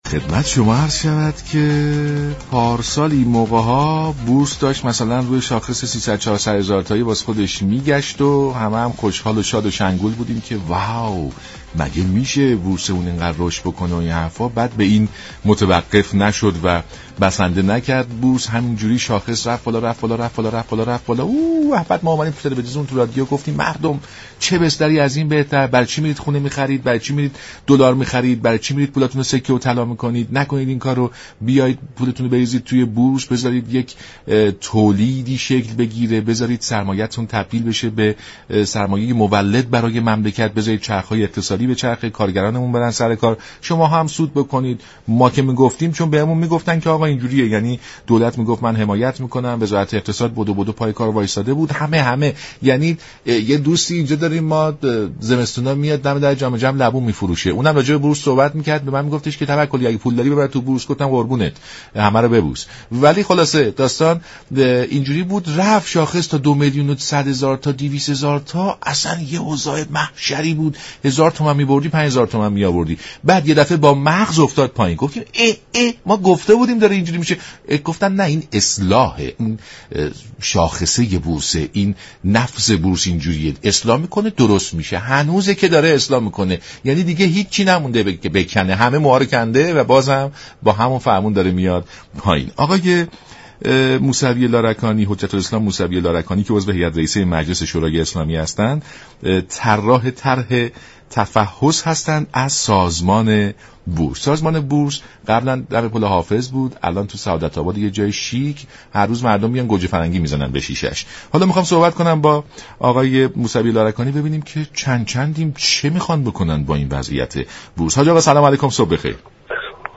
به گزارش شبكه رادیویی ایران، حجت الاسلام والمسلمین سید ناصر موسوی لارگانی عضو كمیسیون اقتصادی در برنامه «سلام صبح بخیر» رادیو ایران درباره جزئیات طرح تحقیق و تفحص از سازمان بورس گفت: طرح  تحقیق و تفحص از سازمان بورس كه به تصویب كمیسیون اقتصادی رسیده هم اكنون آماده رفتن به صحن علنی است.